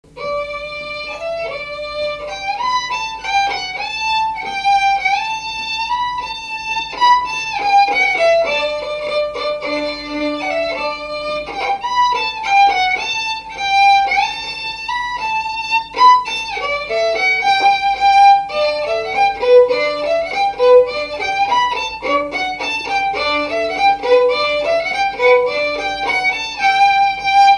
Mémoires et Patrimoines vivants - RaddO est une base de données d'archives iconographiques et sonores.
Marche nuptiale
Résumé instrumental
Pièce musicale inédite